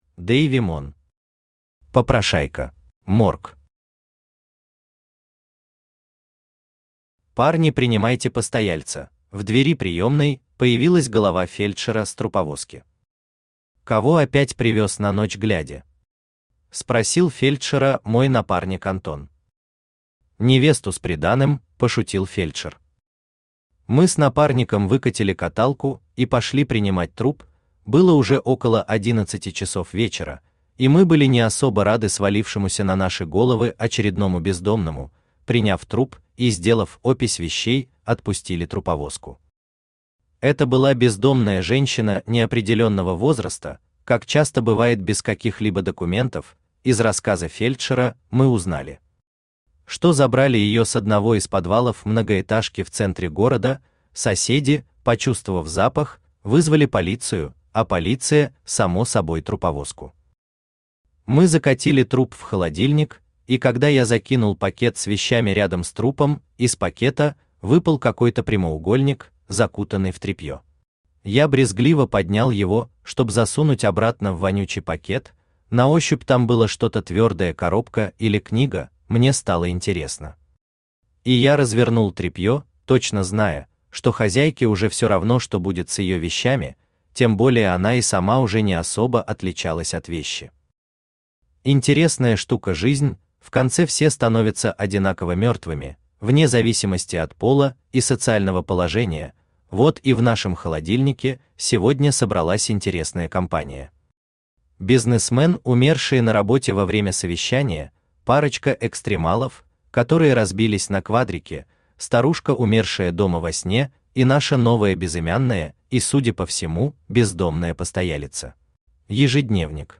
Аудиокнига Попрошайка | Библиотека аудиокниг
Aудиокнига Попрошайка Автор Дейви Мон Читает аудиокнигу Авточтец ЛитРес.